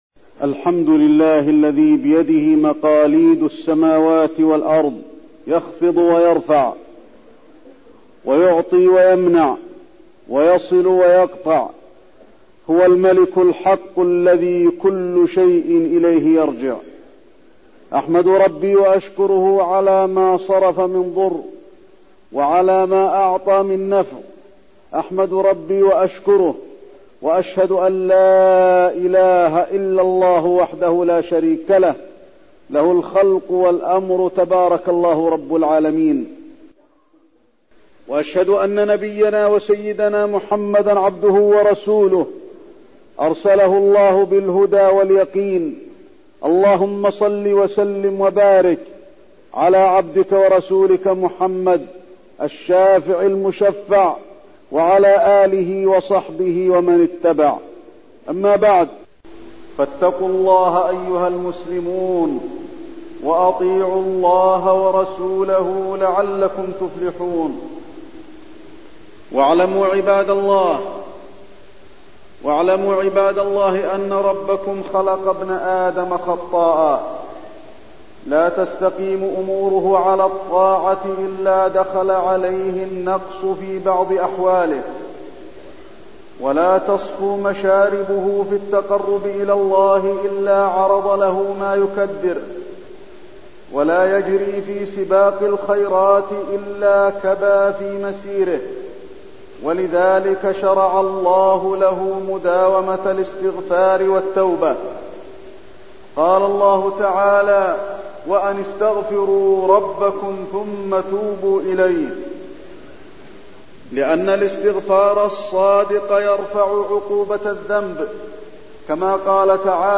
تاريخ النشر ٢٧ شوال ١٤٠٦ هـ المكان: المسجد النبوي الشيخ: فضيلة الشيخ د. علي بن عبدالرحمن الحذيفي فضيلة الشيخ د. علي بن عبدالرحمن الحذيفي التوبة والاستغفار